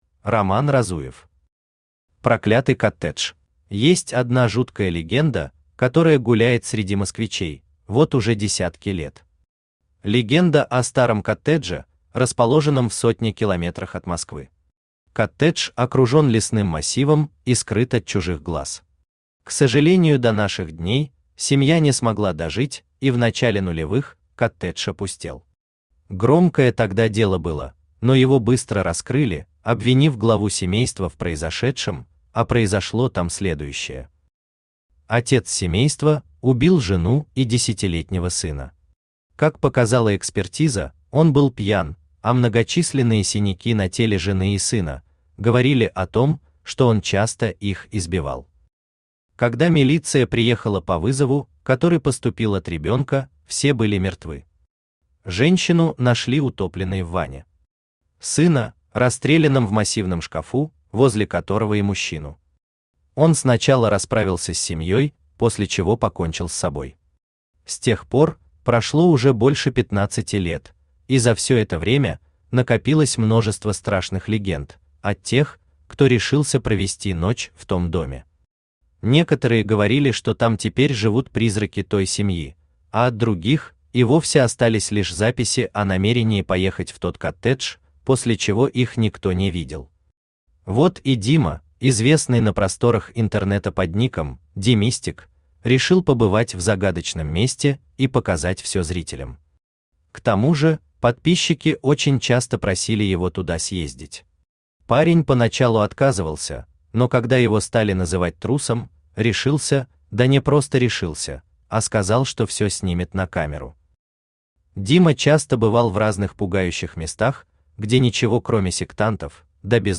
Аудиокнига Проклятый коттедж | Библиотека аудиокниг
Aудиокнига Проклятый коттедж Автор RoMan Разуев Читает аудиокнигу Авточтец ЛитРес.